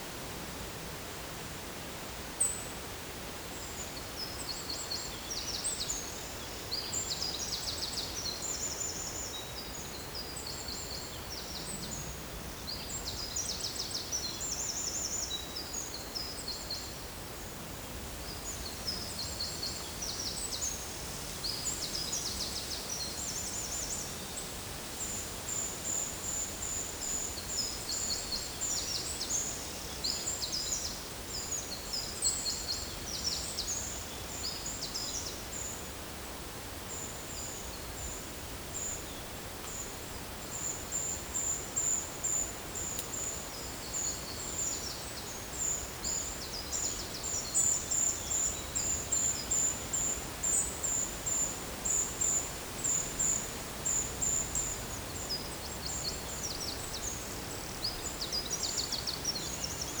Monitor PAM
Troglodytes troglodytes
Certhia brachydactyla
Certhia familiaris